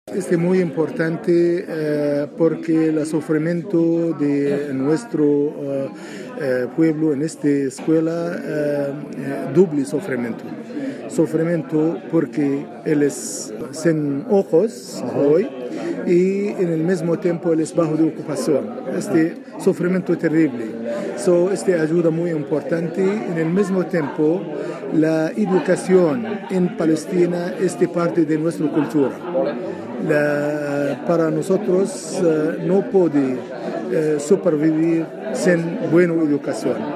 Según reconocía el embajador de Palestina en España, Musa Amer Odeh -también presente en el acto-, es una iniciativa muy importante para la escuela, ya que -según quiso poner en valor durante su intervención- en su país